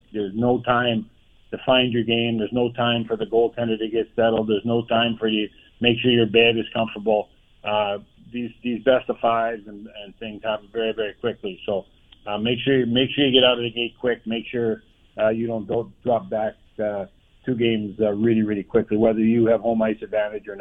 In the interview, Melrose gave his insight into hockey’s return.